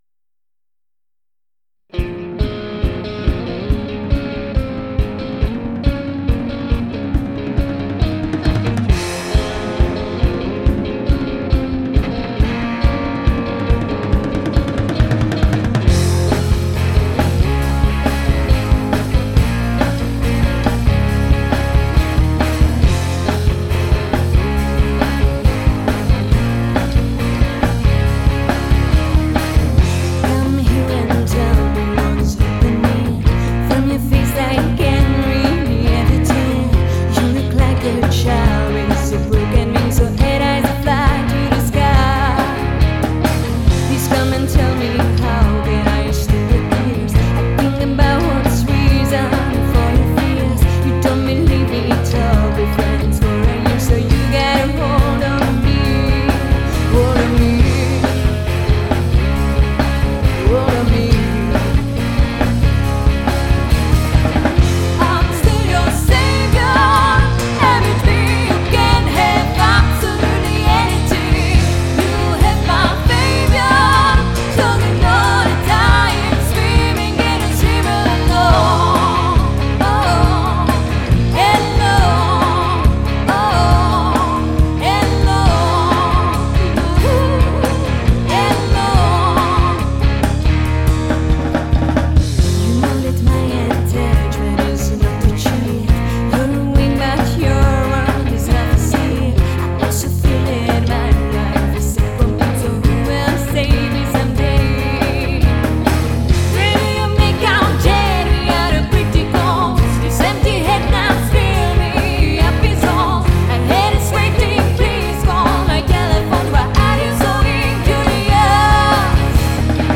No v prvé řadě bych tomu teda vytkl zvuk.
A docela pěkněj hlas.
Uduněný kopák a pořád tam cpe takovej hnusnej činel.
Jednoduchá linka, nemám výhrady.
Basa je trošku utopená v nekvalitním zvuku.
Zpěv supr, kytary ble (ty sóla  roll ) Basa tam kde má být.
Škoda toho hnusnýho zvuku.